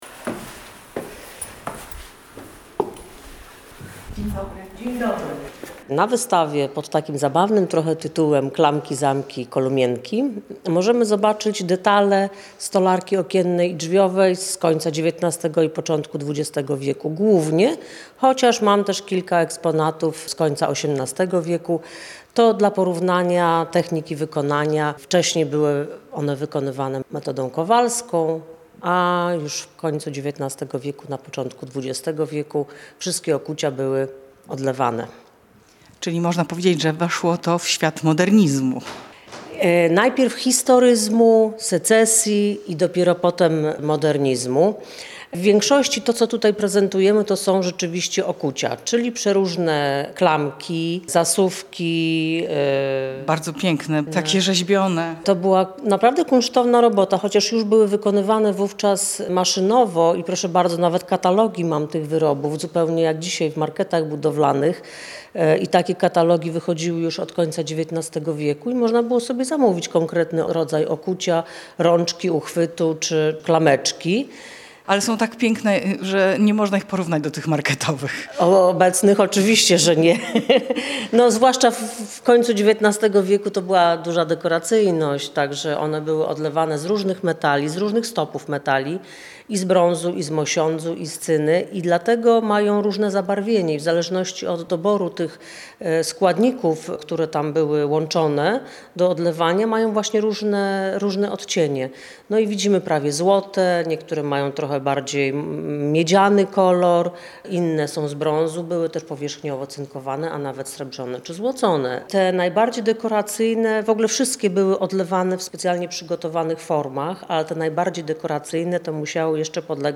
oprowadziła po wystawie